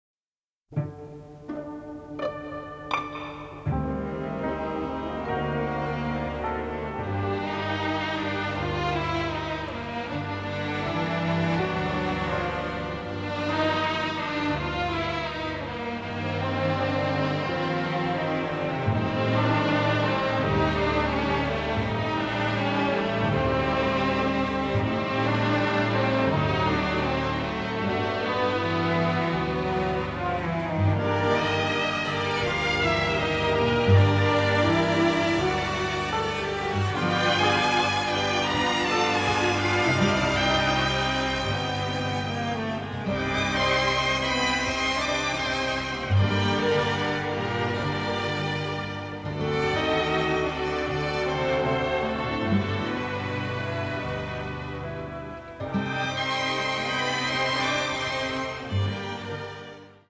The score also features a bossa, a waltz and a tango.